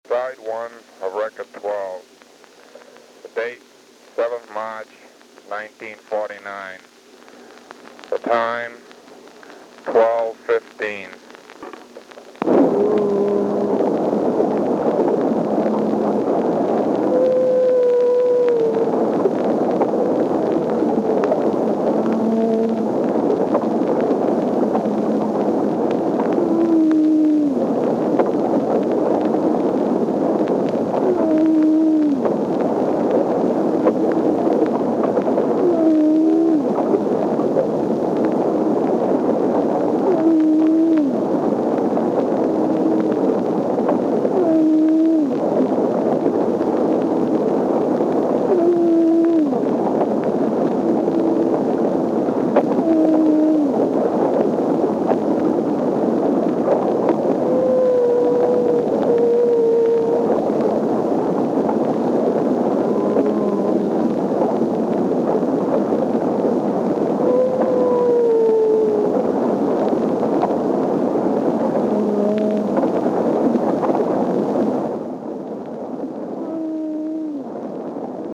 Cet enregistrement a été découvert sur le disque d’un Gray Audiograph, une machine à dicter des années 1940 qui gravait l’audio sur de fins disques de plastique.
Écoutez l’enregistrement audio d’une baleine à bosse capturé par des scientifiques à bord du navire Atlantis lors de la croisière 154 près des Bermudes en 1949.
Un mystère demeure concernant ces enregistrements : à qui appartient la voix en haut du fichier ?
« Face A du disque douze. Date : 7 mars 1949 », annonce une voix masculine, avant que des bulles et des chants de baleines ne s’échappent du micro.